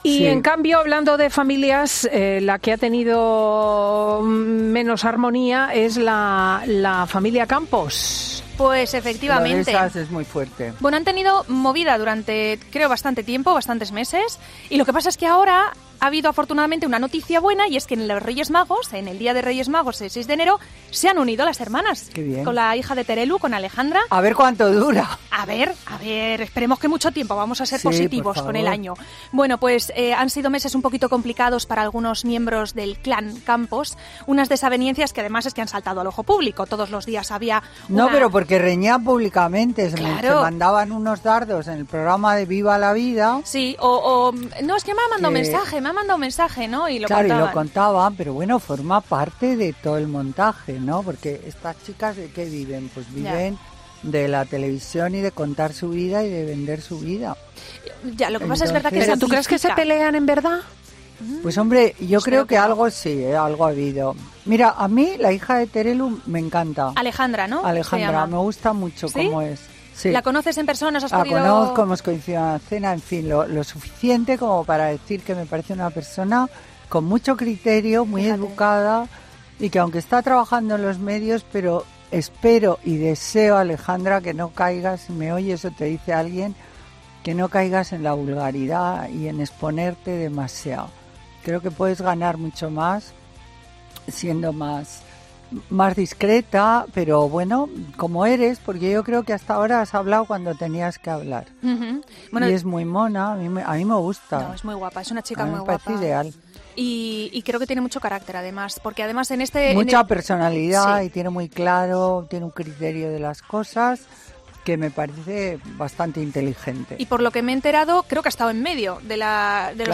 La socialité Carmen Lomana aprovechaba su sección este sábado en Fin de Semana de COPE para lanzar una advertencia a Alejandra Rubia, hija de Terelu Campos, a tenor de la reconciliación de su madre con Carmen Borrego.
“Lo de estas es muy fuerte”, comentaba Lomana en los micrófonos de COPE junto a Cristina López Schlichting.